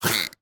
Minecraft Version Minecraft Version latest Latest Release | Latest Snapshot latest / assets / minecraft / sounds / mob / dolphin / attack3.ogg Compare With Compare With Latest Release | Latest Snapshot